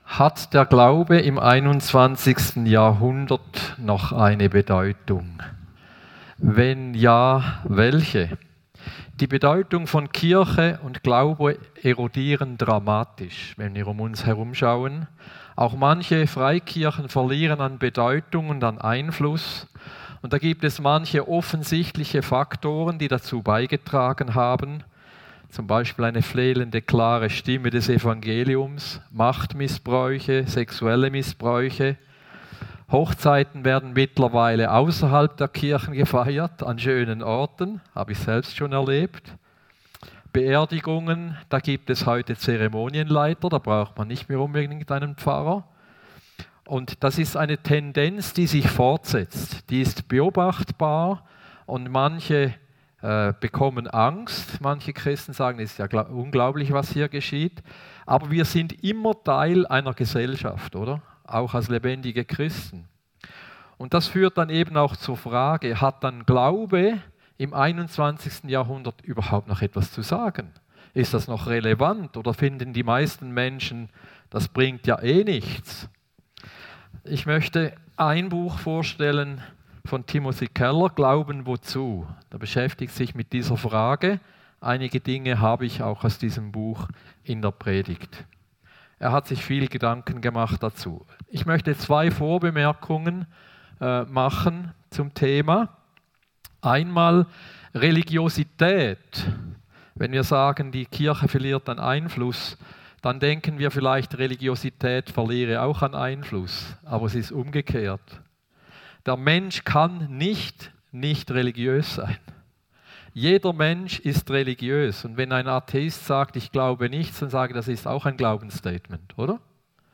Predigt 7. Juli 2024